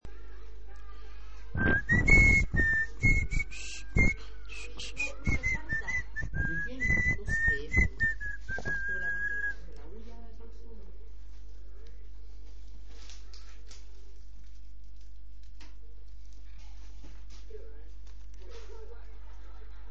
SILVIDO SILVIDO
Ambient sound effects
Silvido_silvido.mp3